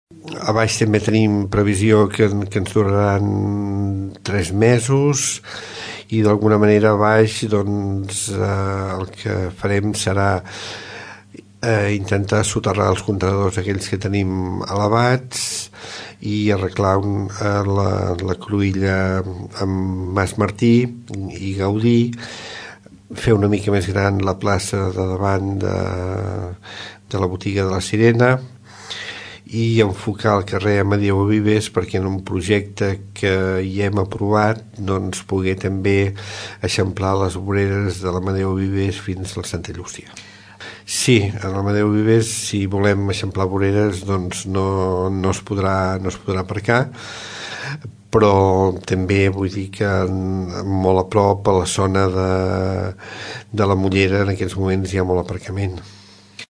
Ho explica el regidor d’obres i serveis, Carles Aulet.